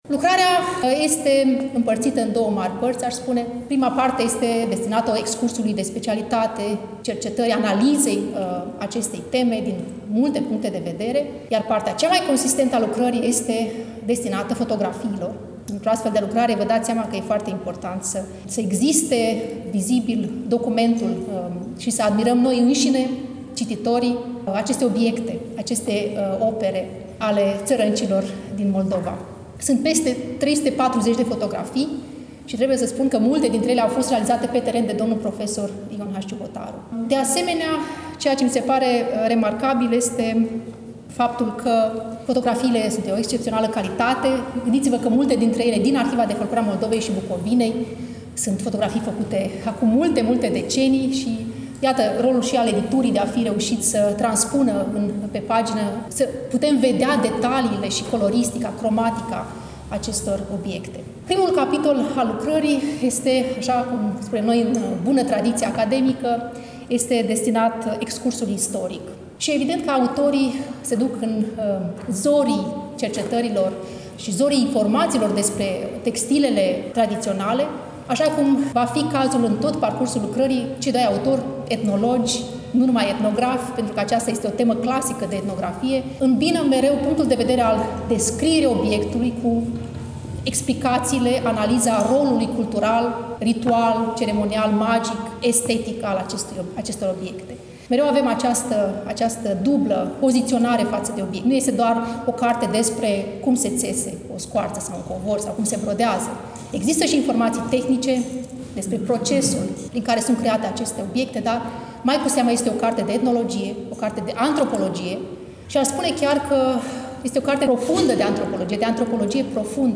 Volumul a fost lansat, la Iași, nu demult, în Sala „Petru Caraman” din incinta Muzeului Etnografic al Moldovei, Palatul Culturii.